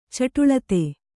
♪ caṭulate